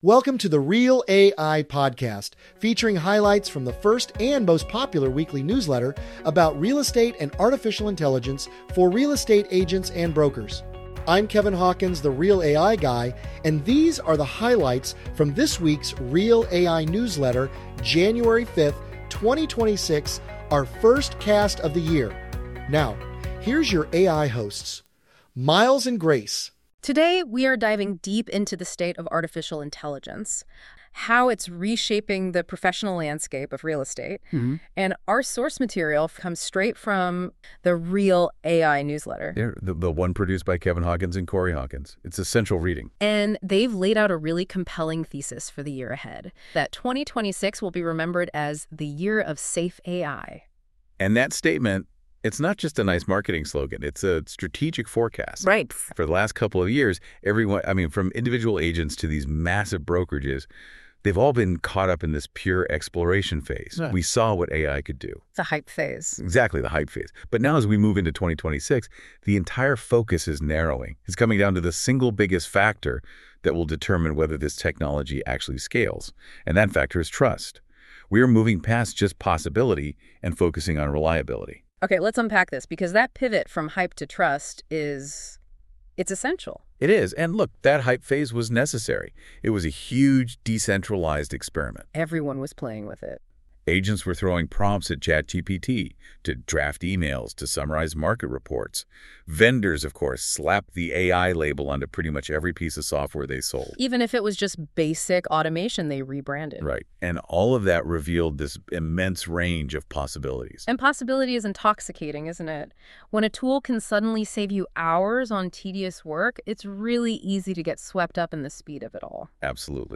AI generated